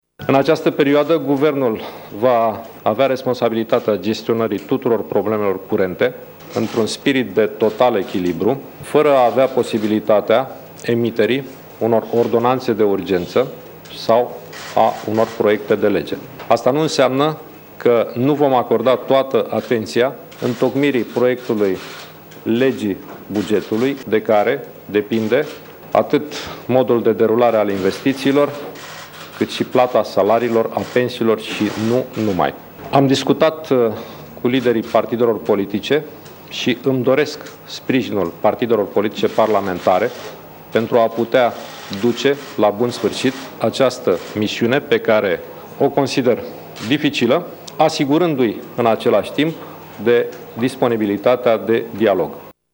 El a anunţat, într-o conferinţă de presă susţinută la Palatul Victoria, că i-a convocat pe toţi miniştrii la o şedinţă informală, care va avea loc la ora 16, la sediul Guvernului.